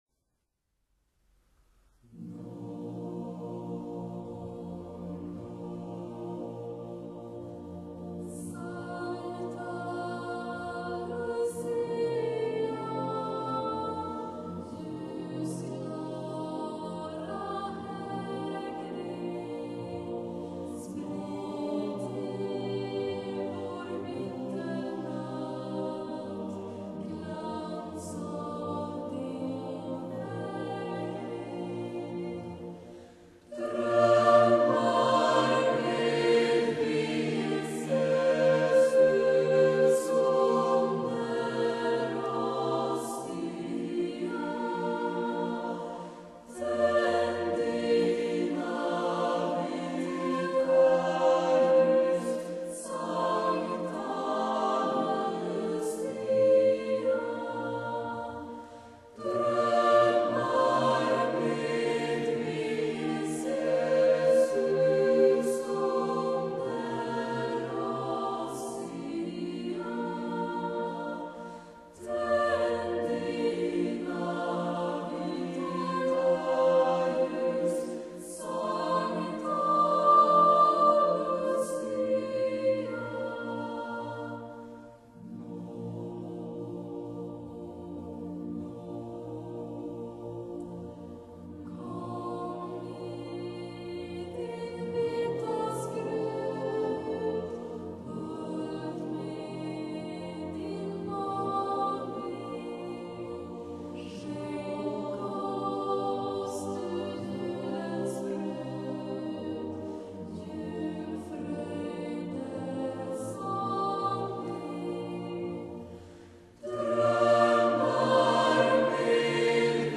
尤其兩位女聲領唱，聲線仙氣十足，美到離奇。